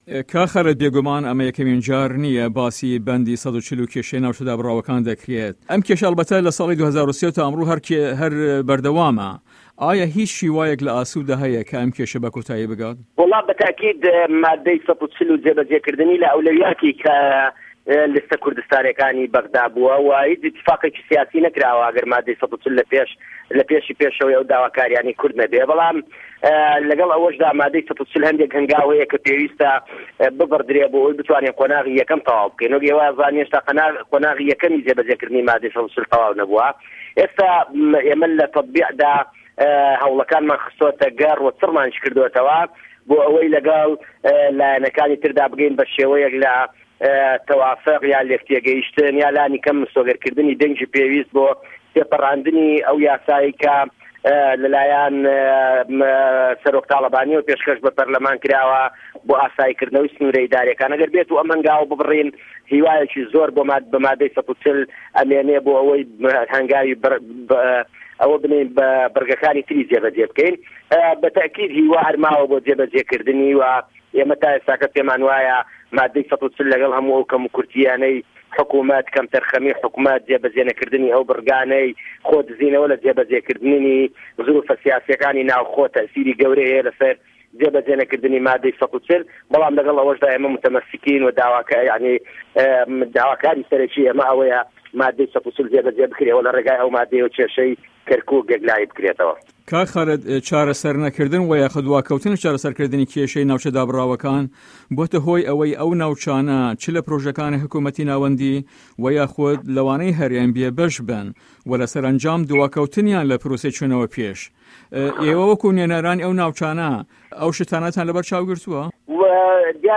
وتو وێژ له‌گه‌ڵ خالید شوانی